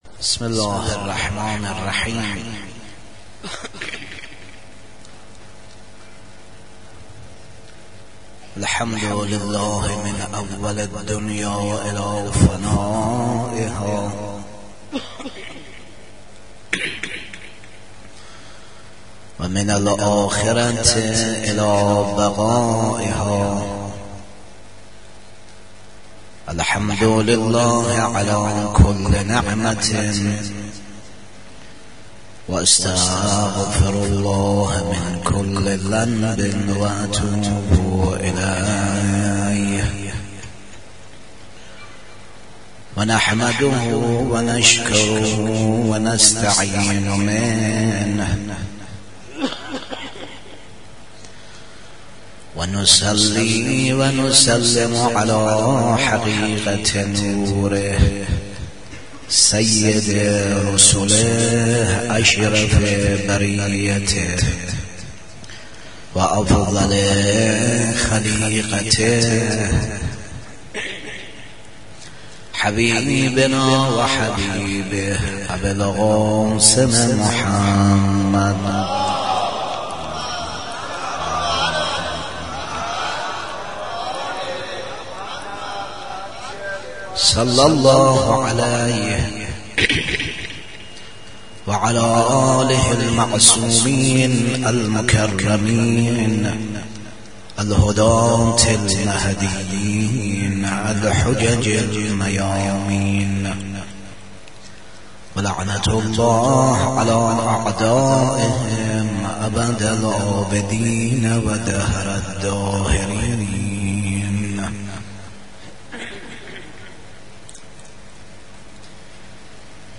آرشیو ماه مبارک رمضان - سخنرانی - بخش هفتم